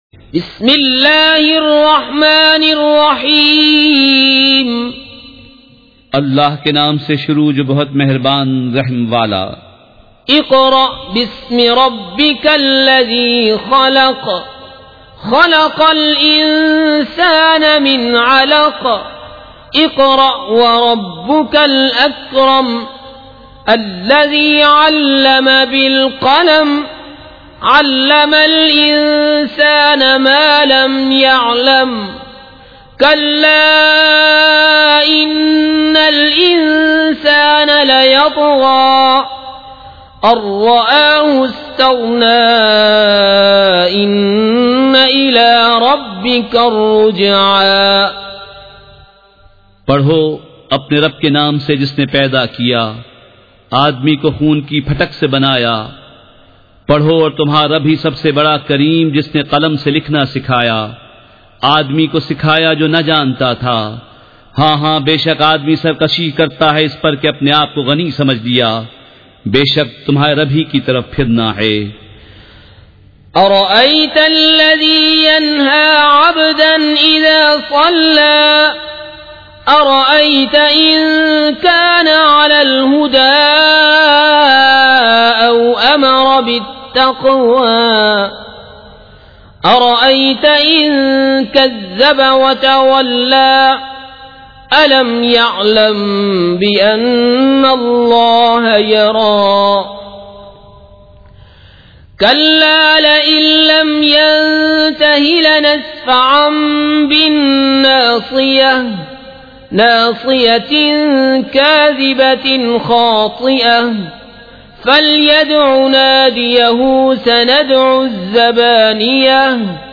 سورۃ العلق مع ترجمہ کنزالایمان ZiaeTaiba Audio میڈیا کی معلومات نام سورۃ العلق مع ترجمہ کنزالایمان موضوع تلاوت آواز دیگر زبان عربی کل نتائج 1825 قسم آڈیو ڈاؤن لوڈ MP 3 ڈاؤن لوڈ MP 4 متعلقہ تجویزوآراء